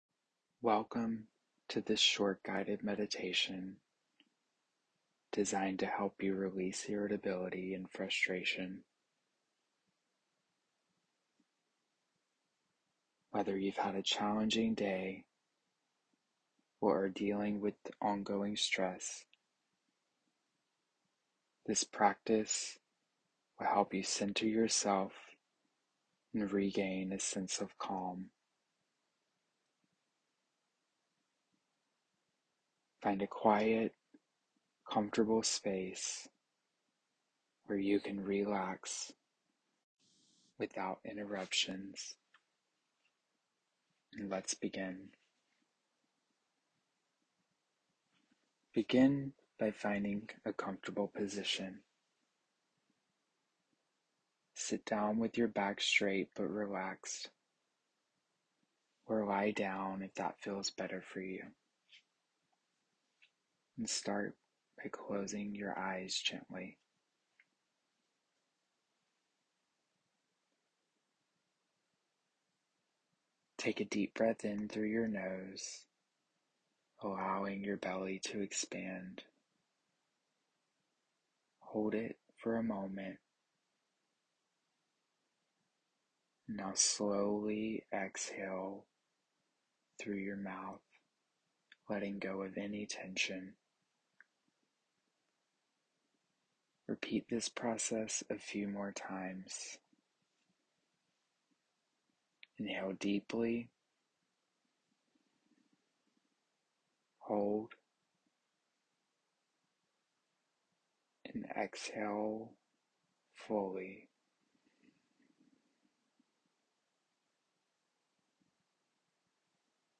Guided Meditation for Managing Irritability & Releasing Stress